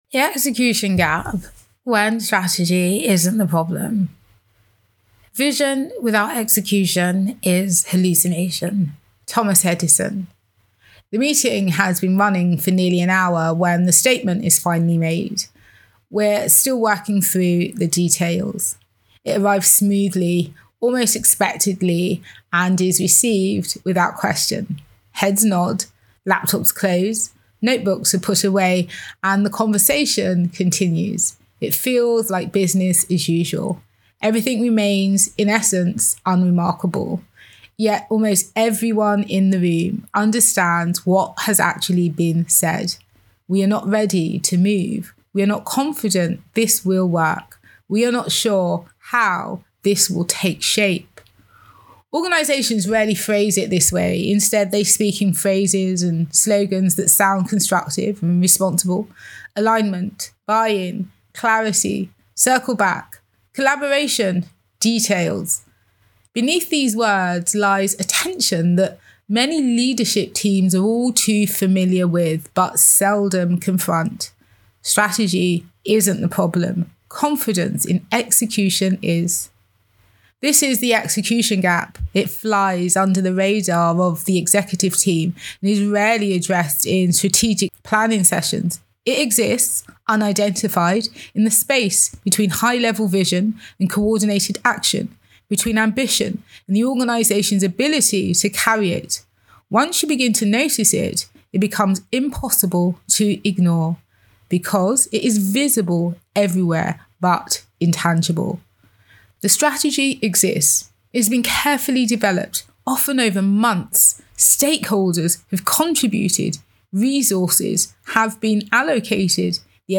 Audio Commentary In this audio commentary, I explore the execution gap and the growing distance between strategy and day-to-day work.
when-strategy-isnt-the-problem-execution-gap-audio-commentary.mp3